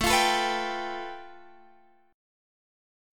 Listen to A7sus2#5 strummed